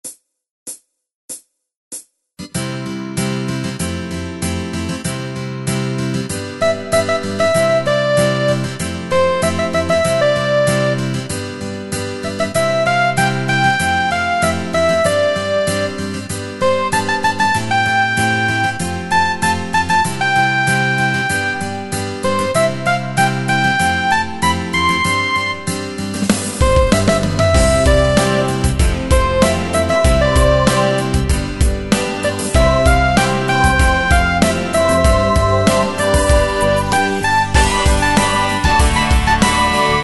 Tempo: 96 BPM.
MP3 with melody DEMO 30s (0.5 MB)zdarma